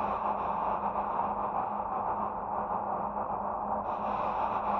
SS_CreepVoxLoopA-11.wav